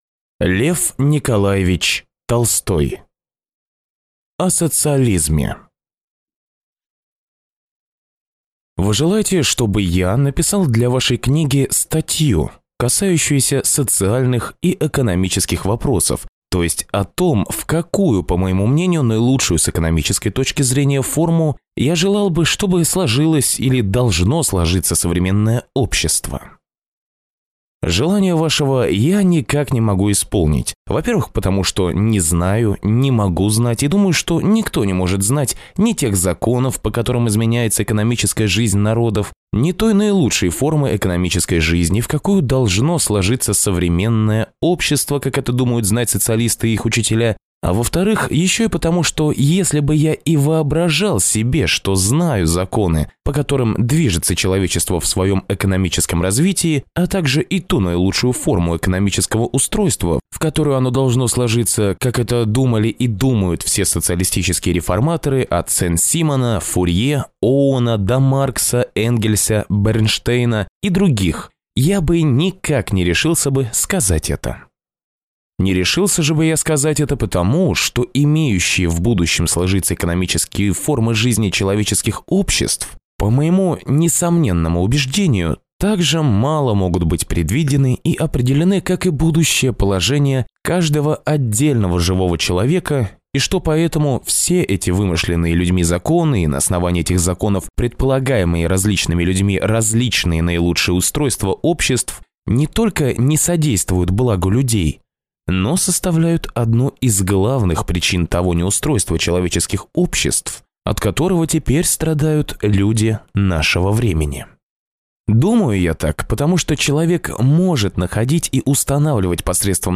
Аудиокнига О социализме | Библиотека аудиокниг